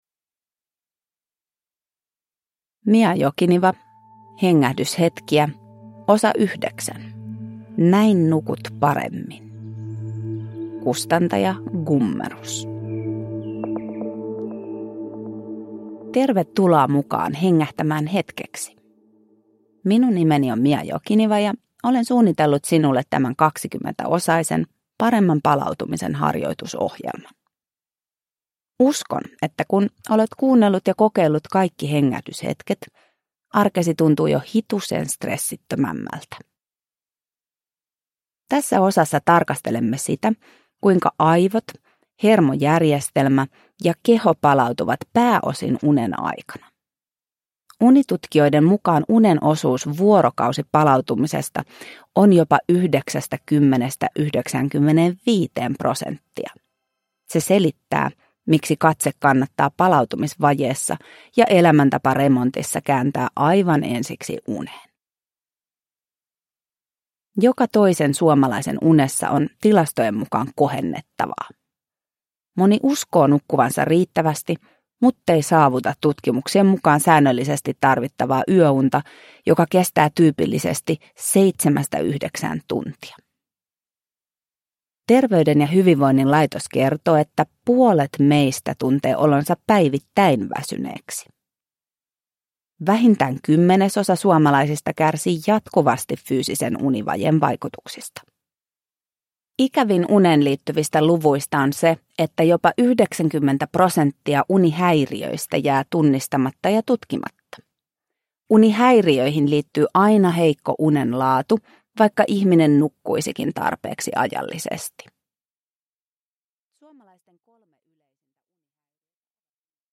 Hengähdyshetkiä (ljudbok